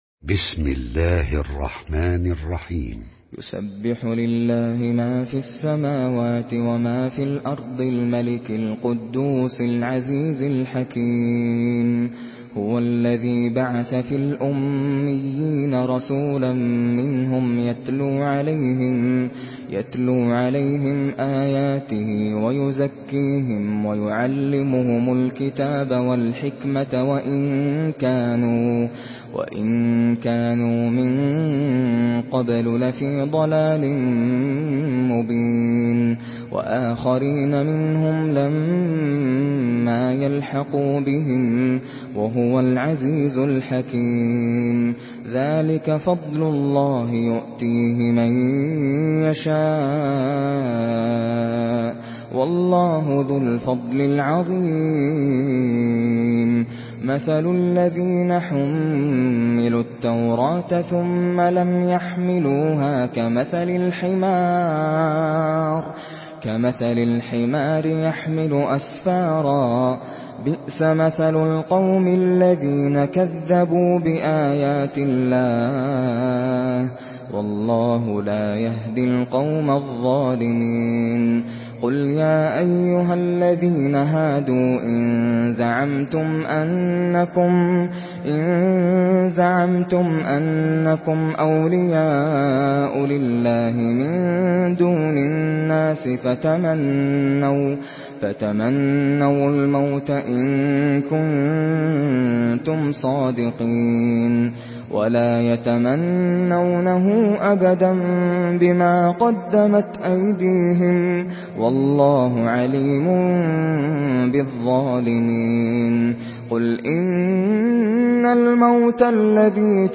Surah Cüma MP3 by Nasser Al Qatami in Hafs An Asim narration.
Murattal Hafs An Asim